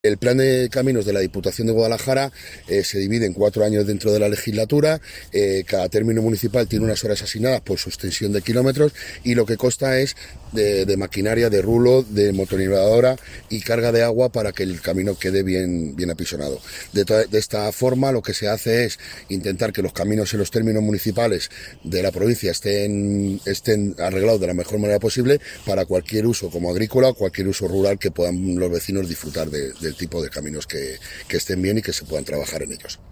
Declaraciones del vicepresidente segundo Pedro David Pardo